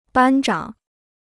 班长 (bān zhǎng): class monitor; squad leader.